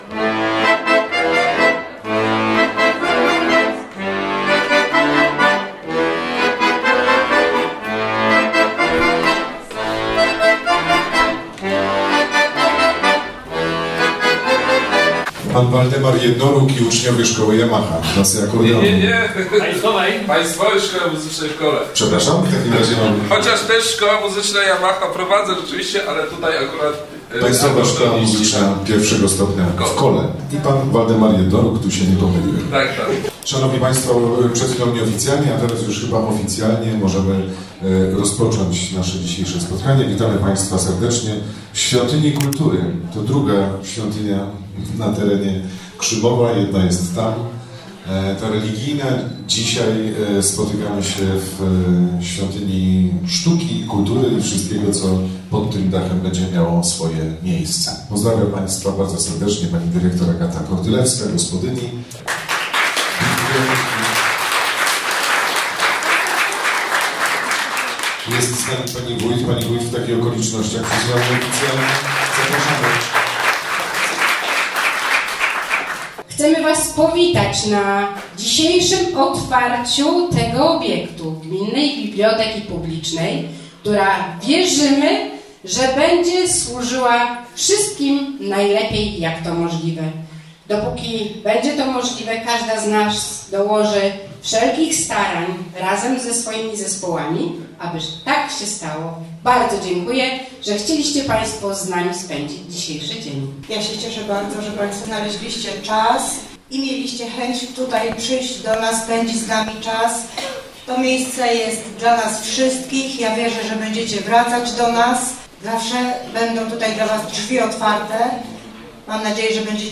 Tadeusz Chudecki, aktor i podróżnik spotkał się z mieszkańcami naszej gminy w Gminnej Bibliotece Publicznej w Krzymowie.
Tadeusz Chudecki z lekkością opowiadał o odwiedzonych miejscach, dodawał anegdotyczne przygody, jakie go spotykały podczas niektórych wypraw.